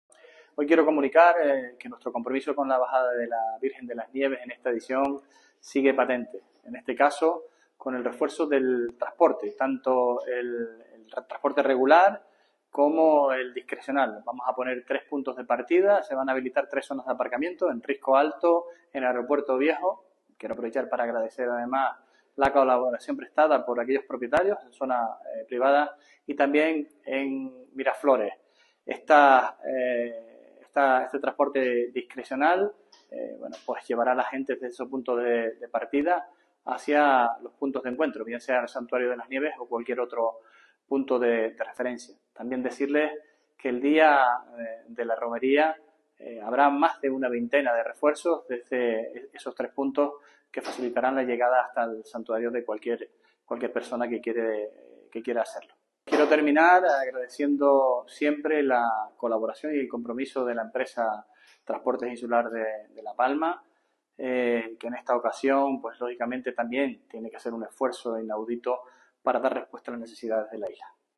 Declarciones audio Sergio Rodríguez transportes.mp3